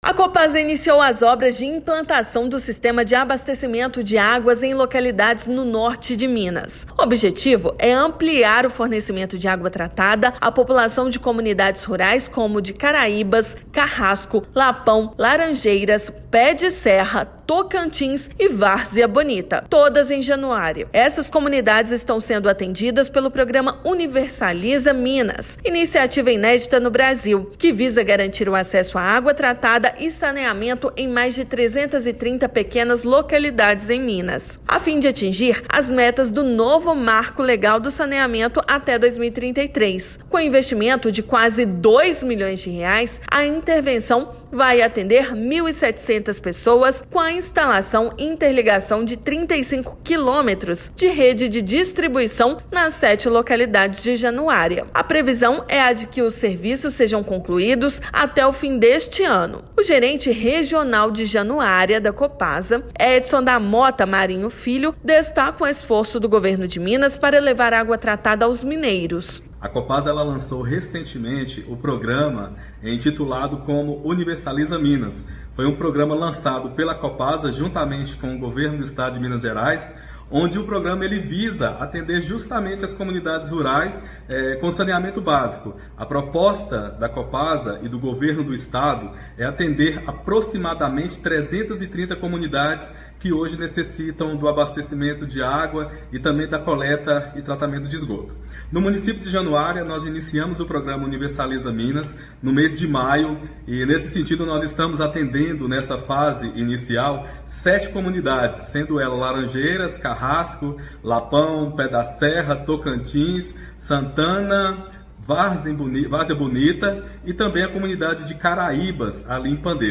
[RÁDIO] Copasa garantirá água tratada a 1,7 mil moradores da zona rural de Januária
Com investimentos de quase R$ 2 milhões, obras do Universaliza Minas chegam a mais sete distritos da cidade. Ouça matéria de rádio.